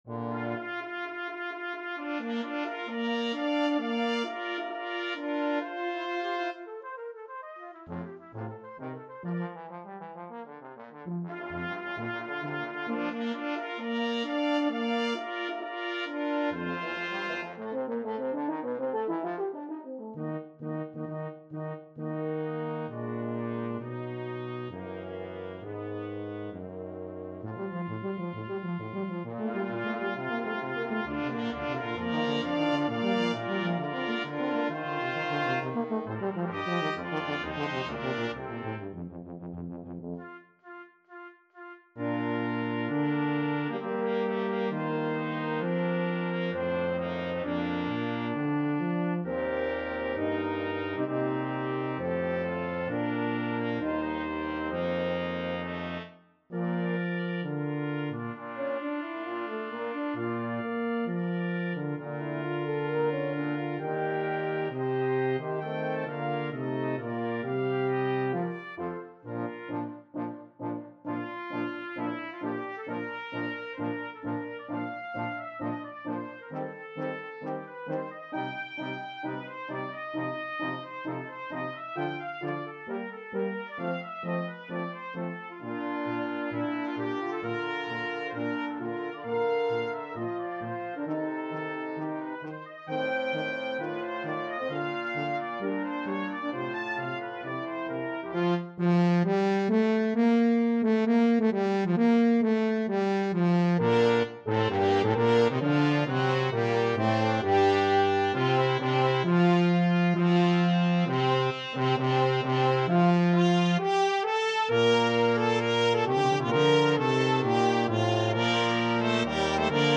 for Brass quintet
2 Trumpets
1 French Horn
1 Trombone
1 Tuba